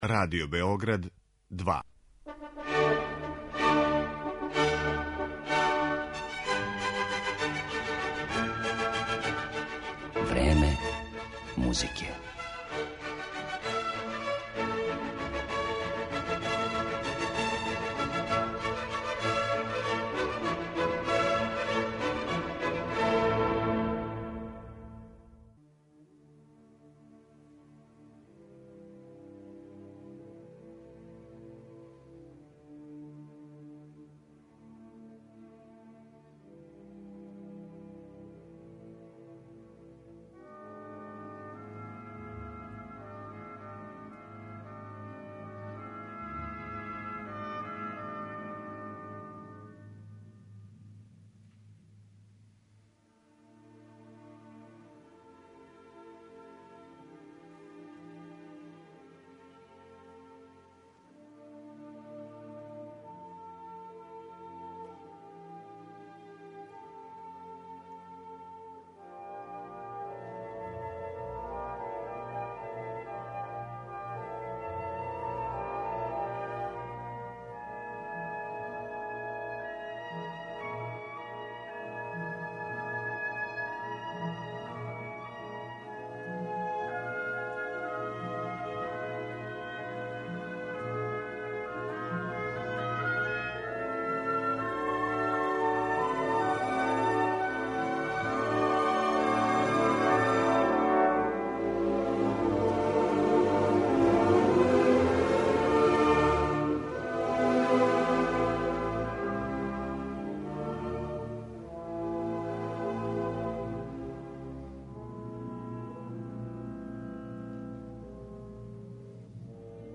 Избаране фрагменте из композиција ових аутора слушаћете у извођењу врхунских оркестара и вокалних солиста.